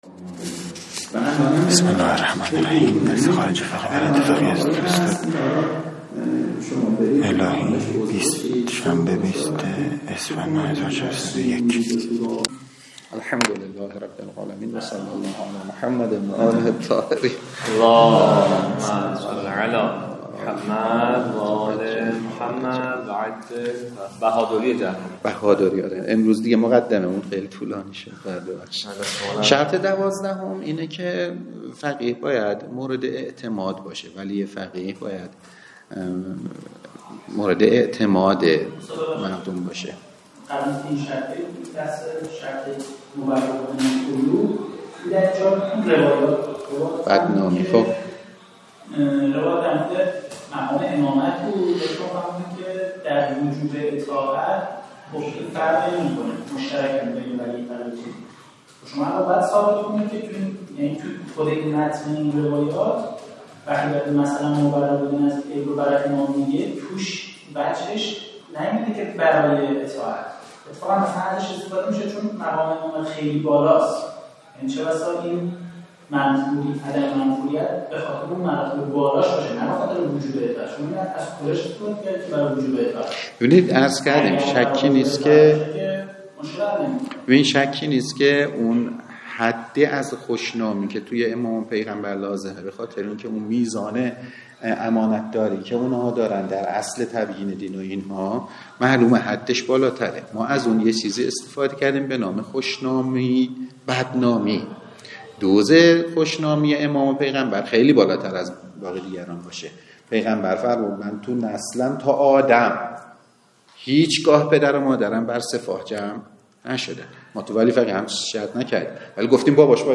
درس خارج فقه ولایت فقیه